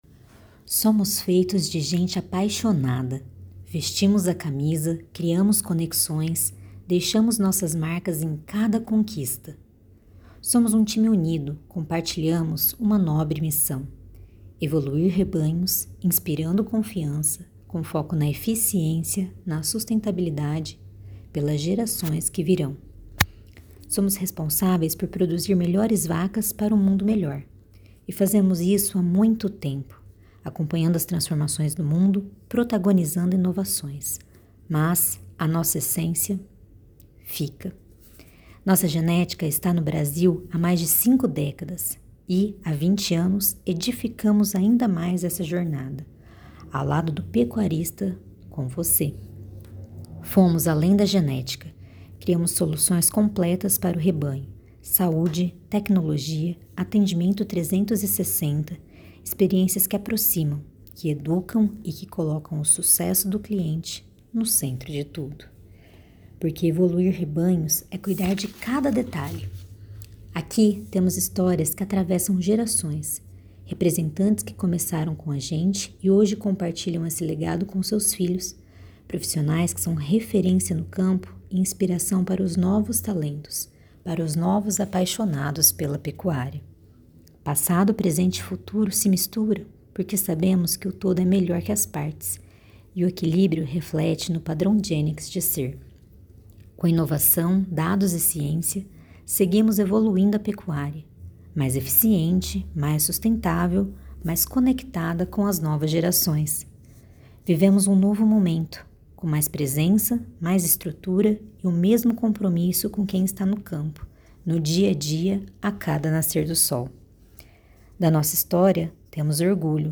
GENEX pronuncia "GE" como em Jennifer falado em inglês "DJênex", colocando a tônica na primeira silaba e deixando suave o x ao final.
Gostaríamos, na medida do possível, pensando em estilo, que ficasse algo como a propaganda do Itau com a Fernanda Montenegro: